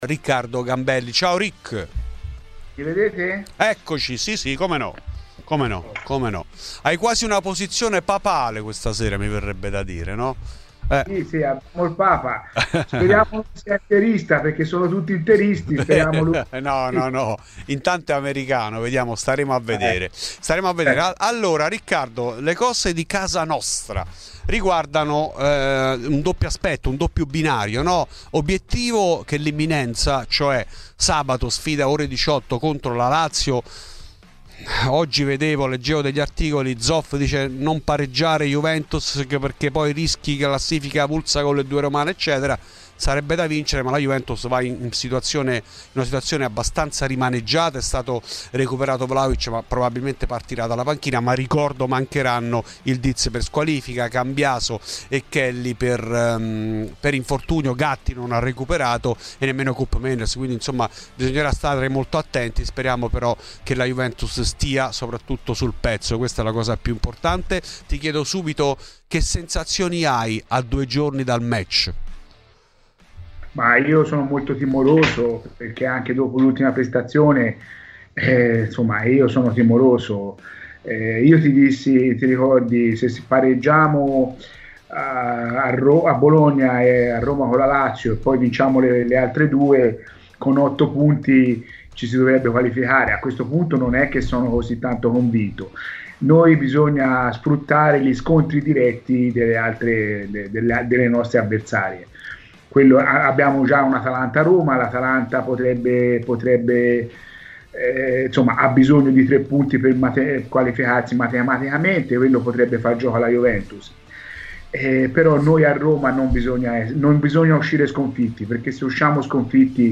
ospite di Fuori di Juve